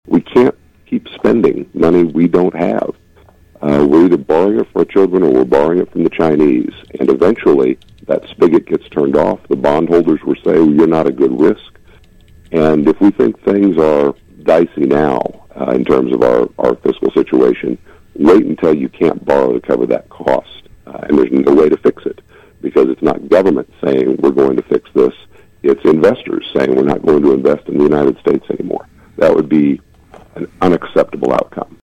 There have been different categories of work, but Kansas Second District Congressman Derek Schmidt — a guest on KVOE’s Morning Show on Friday — says those categories can all be funneled into work to shrink the budget and, specifically, the nation’s debt.
Friday’s interview was the first of Schmidt’s monthly updates on KVOE.